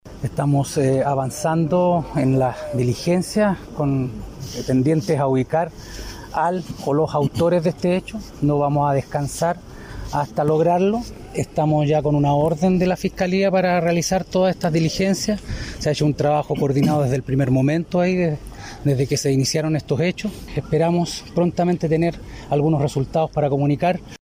El director general de la PDI, Sergio Muñoz, calificó el hecho como un atentado y mencionó el avance de las diligencias para detener a los responsables.